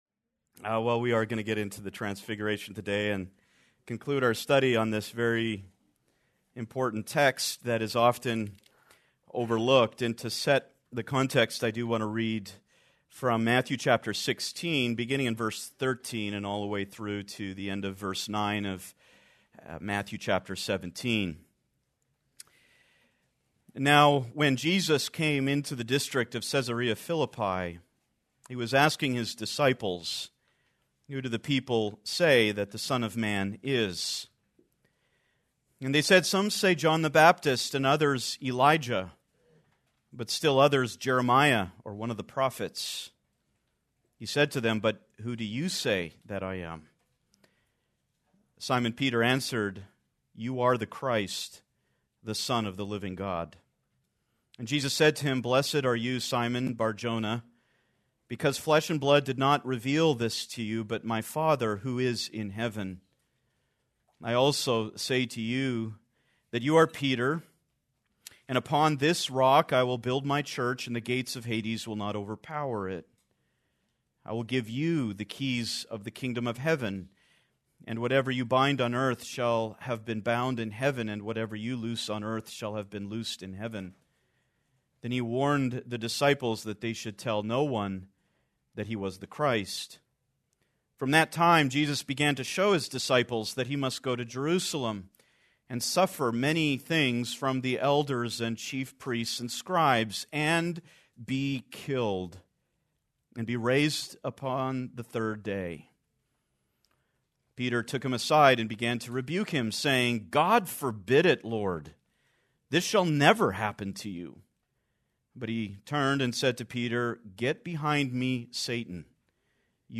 Please note, due to technical difficulties, this recording skips brief portions of audio.